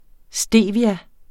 Udtale [ ˈsdeˀvia ]